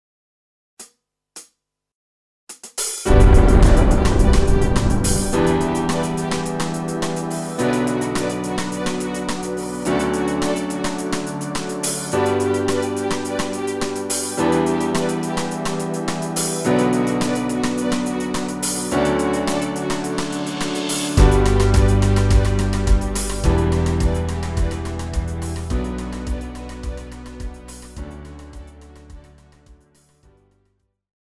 Žánr: Rock
BPM: 106
Key: D
GM ukázka